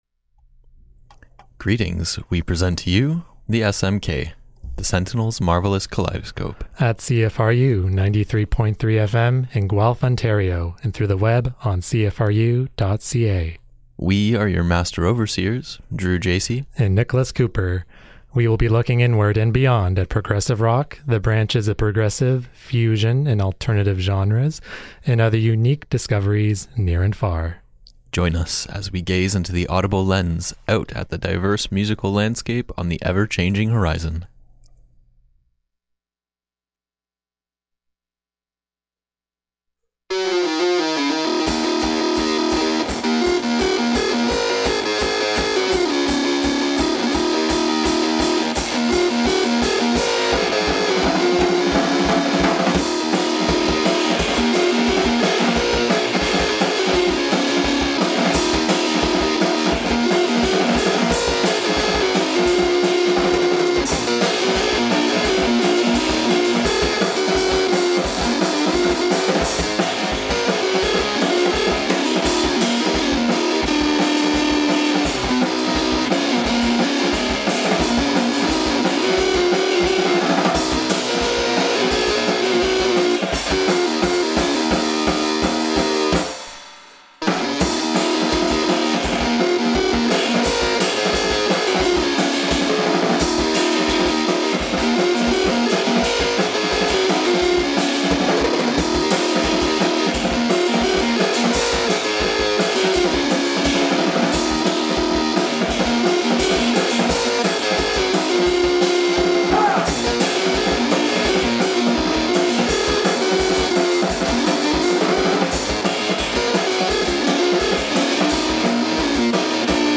Looking inward and beyond at progressive, fusion and alternative genres, near and far...